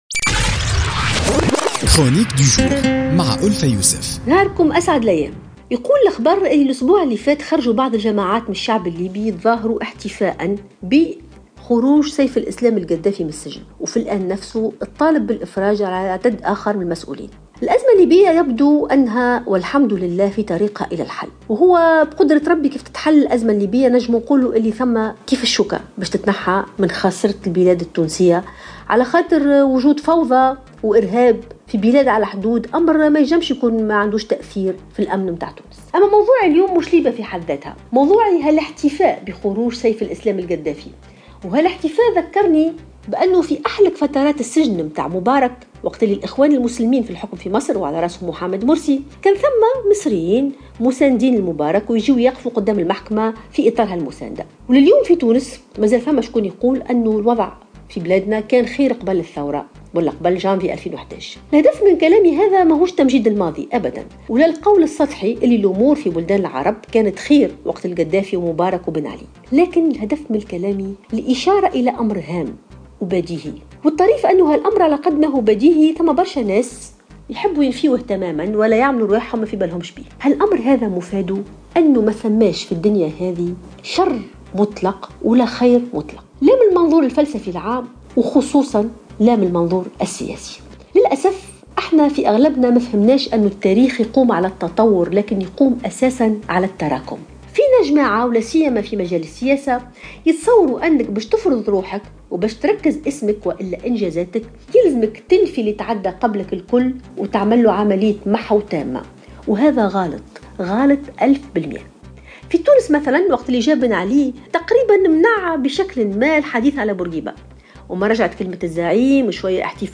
اعتبرت الكاتبة ألفة يوسف في افتتاحيتها اليوم الخميس بـ "الجوهرة أف أم" ان حرص السياسيين في تونس على محو التاريخ عمل خاطئ.